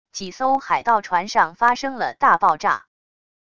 几艘海盗船上发生了大爆炸wav音频